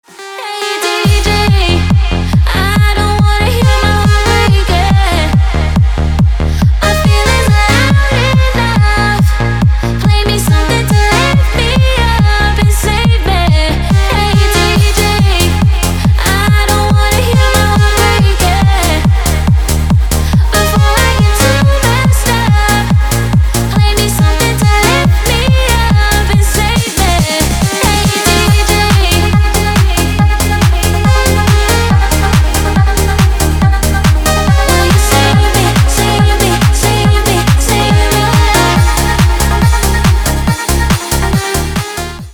Dance рингтоны